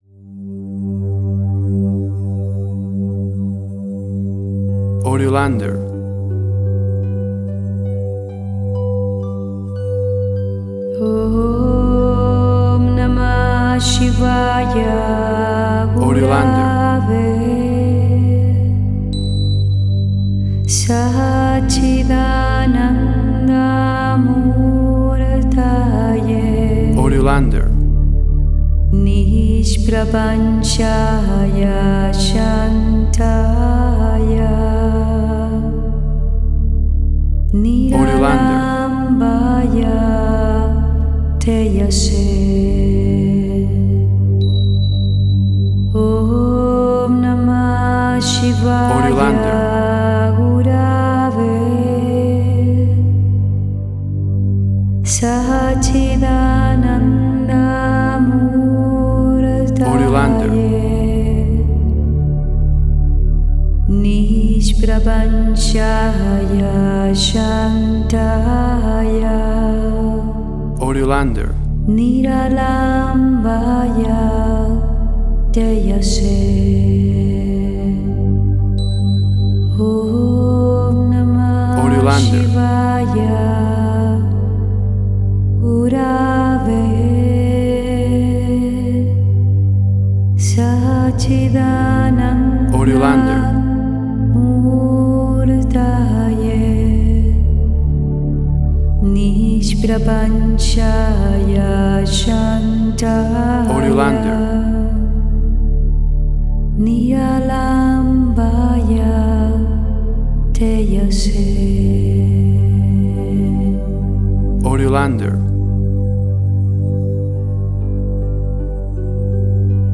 WAV Sample Rate: 24-Bit stereo, 48.0 kHz